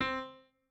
piano3_21.ogg